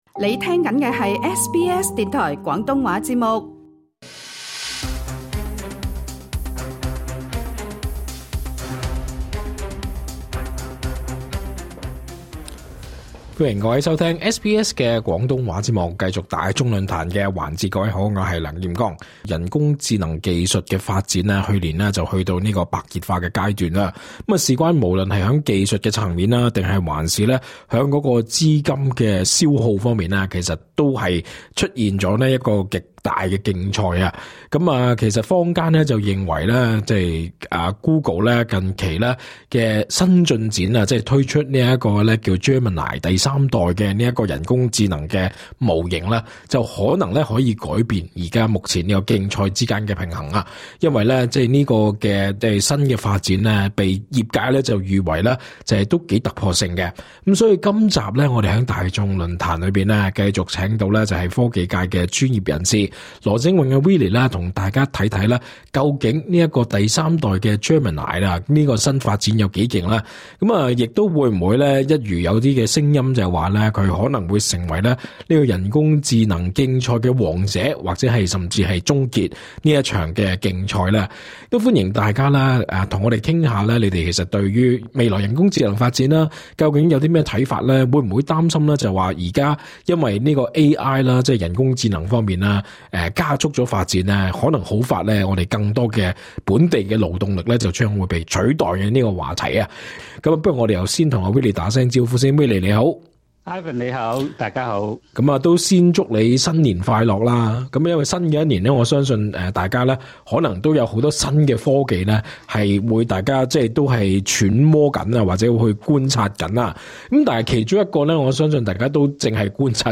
更多詳情請收聽【大眾論壇】的完整訪談。